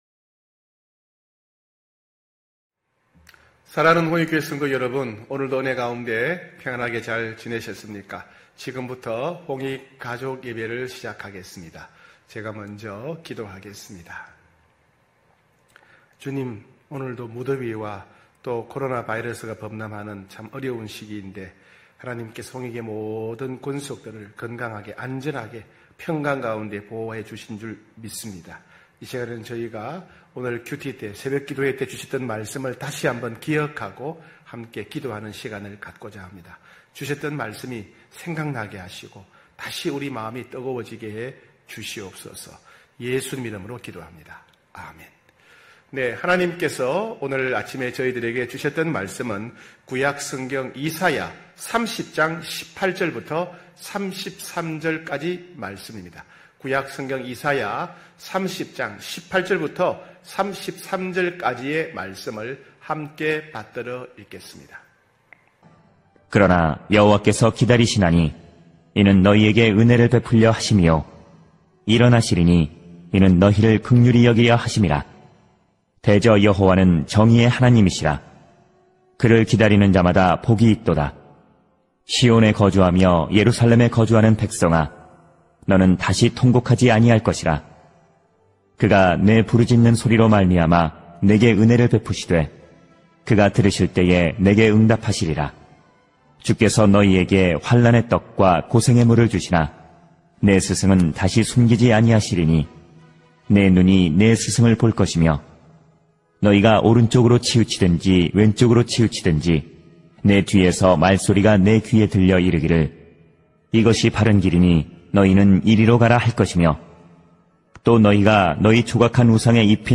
9시홍익가족예배(8월21일).mp3